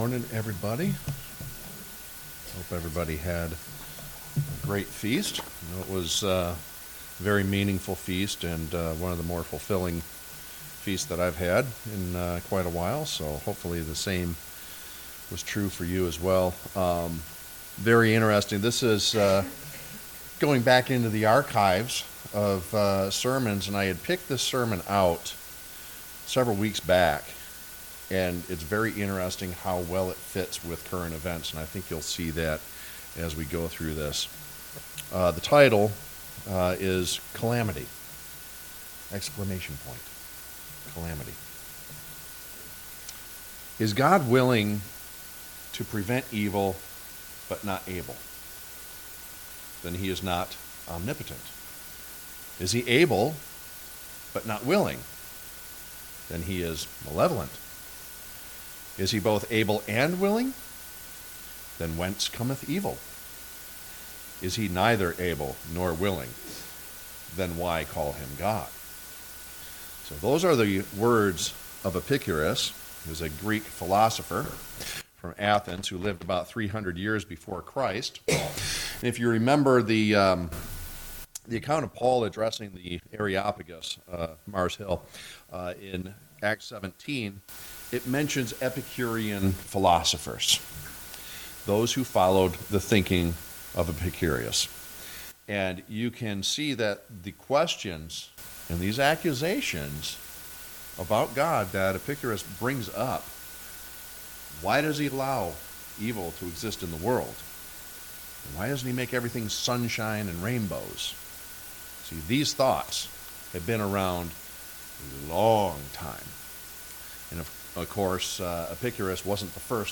Given in Freeland, MI